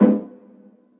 Metro Classic Perc.wav